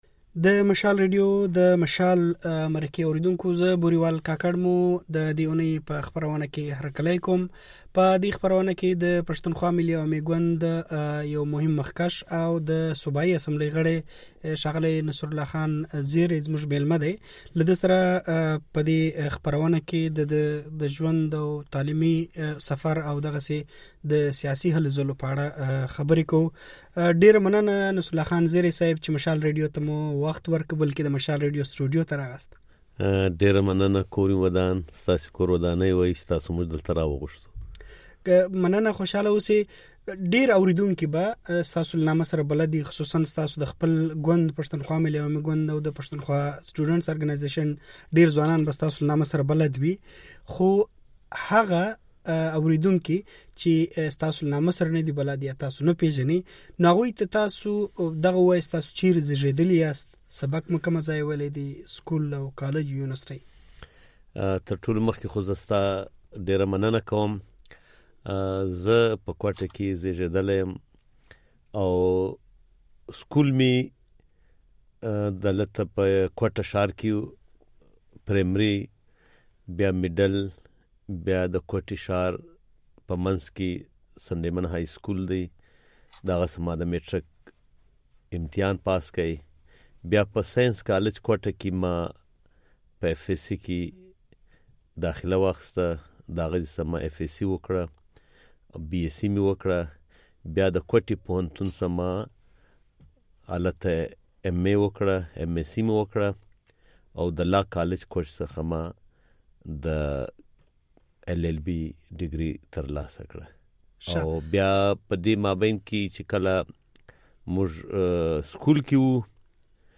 د نصرالله زېري مرکه دلته واورئ